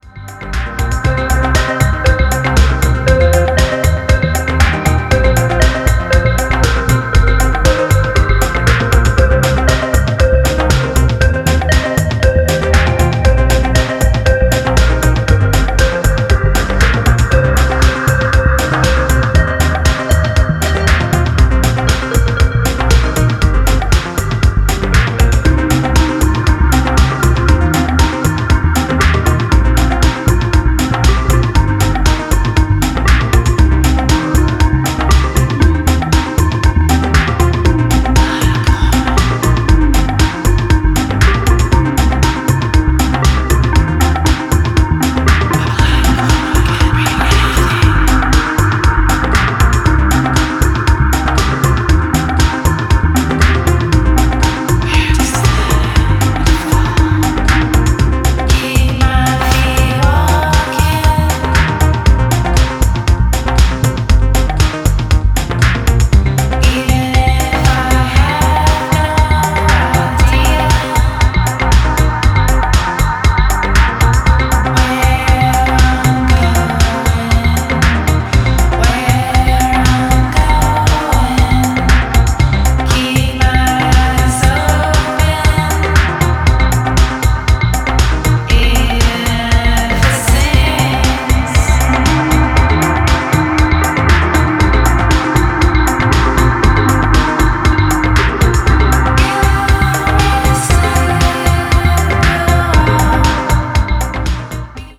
Californian electronic pop duo